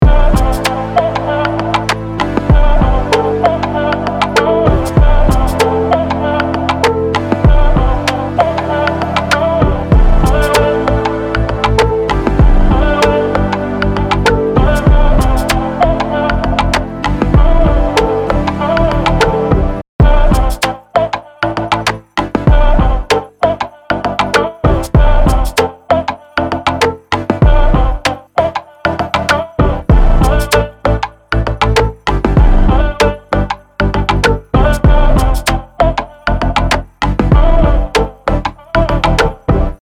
Vocal Loop | Preset: Sidechain Triggering (DRY→WET)
Articulate-Vocal-Loop-Sidechain-Triggering-2.mp3